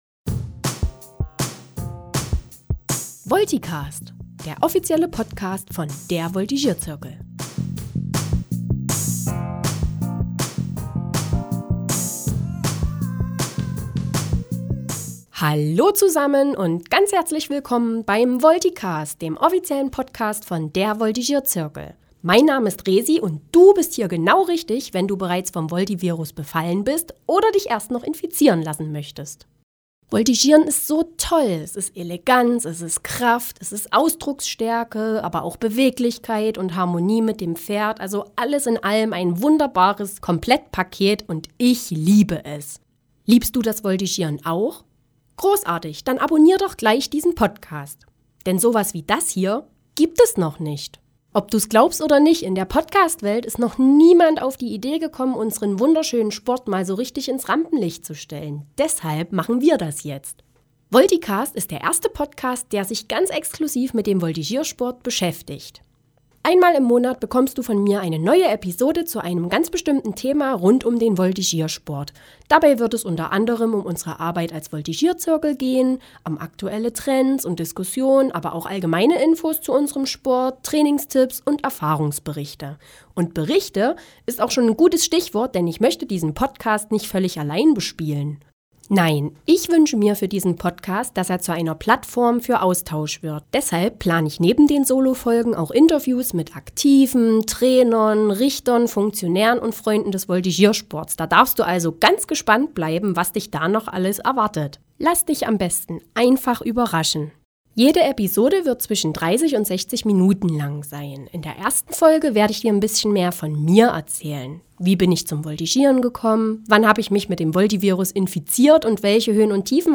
Musik Intro/Outro